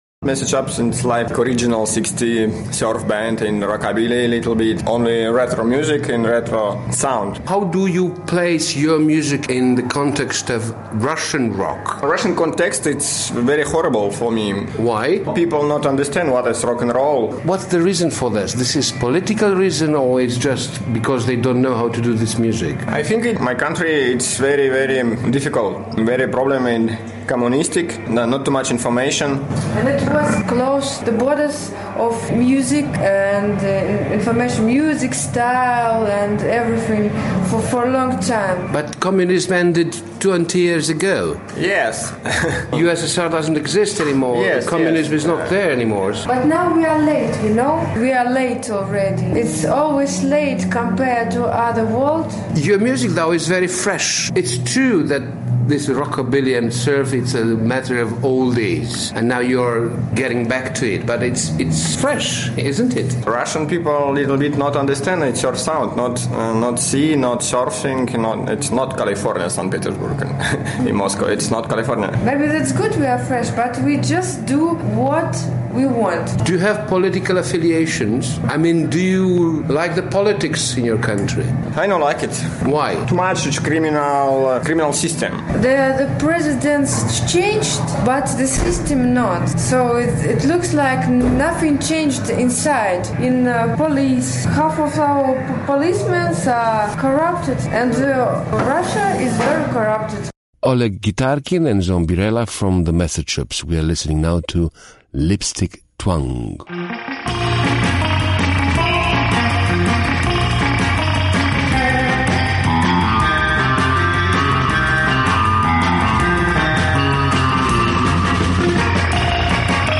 Interviu
Praga